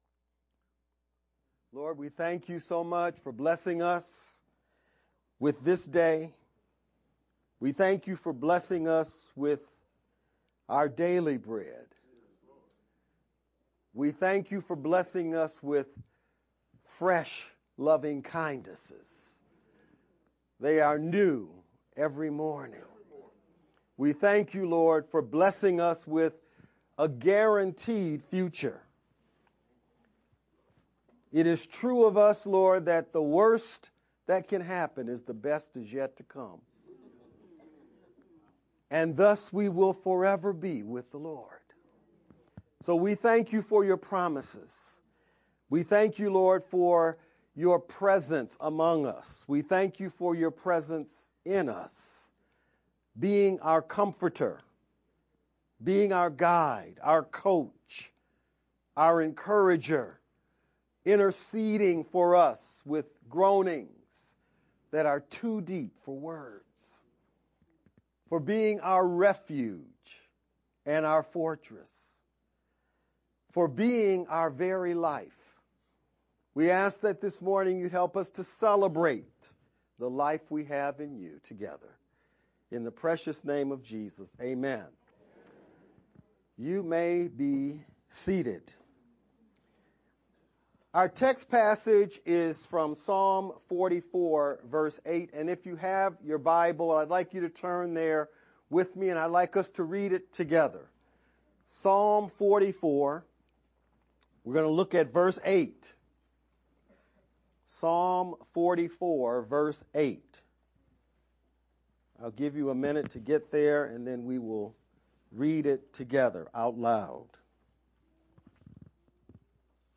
VBCC-Sermon-only-edited-5-18_Converted-CD.mp3